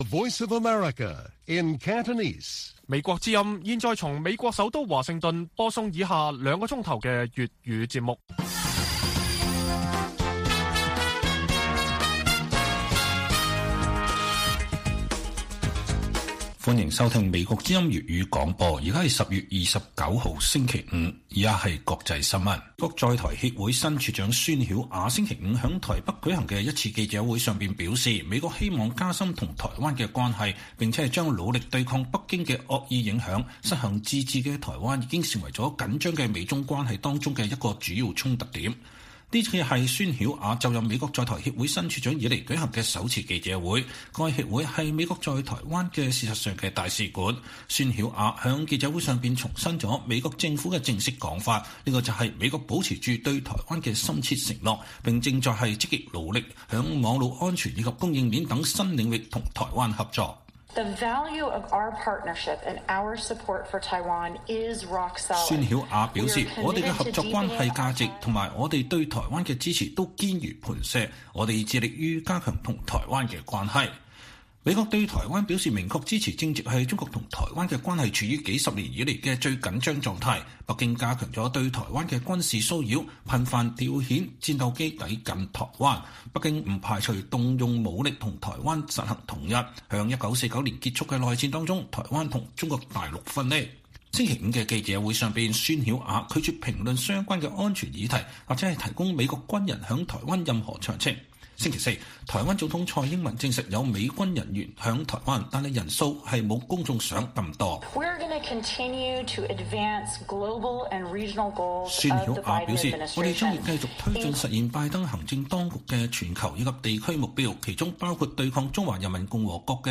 粵語新聞 晚上9-10點： 美國在台協會新處長申明面對中國威逼加深與台關係